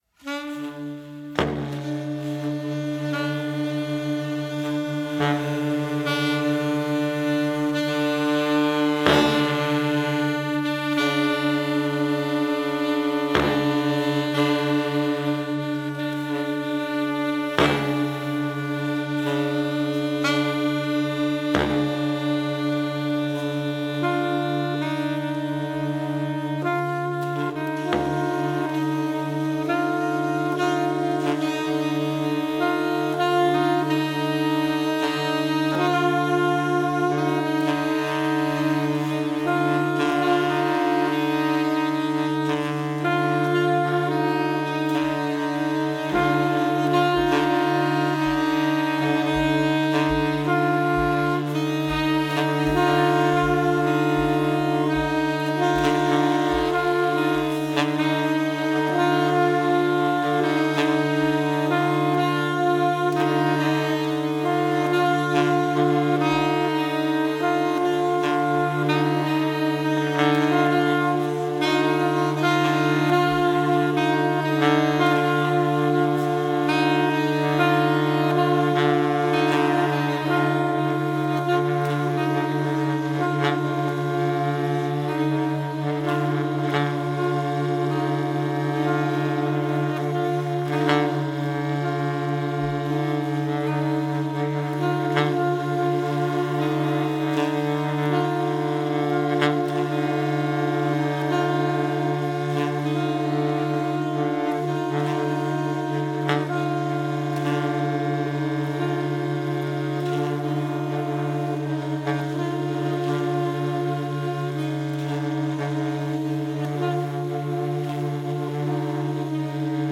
free jazz and improvised music
alto saxophone
alto, sopranino and baritone saxophones
soprano and tenor saxophones
sopranino, soprano and bass saxophones
in Hietsun Paviljonki, Helsinki